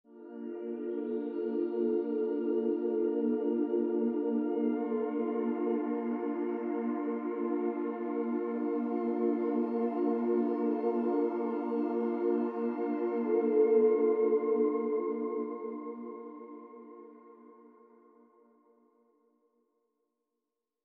crystals_and_voices.mp3